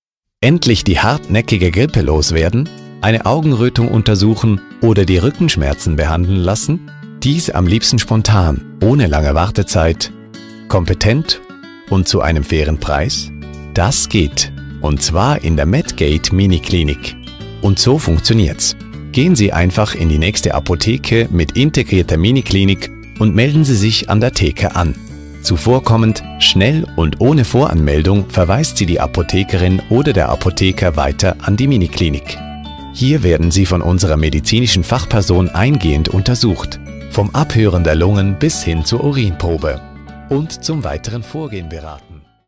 OFF-Voice Hochdeutsch (CH)
Erfahrene Stimme mit langjähriger Erfahrung im professionellen Sprecherbereich.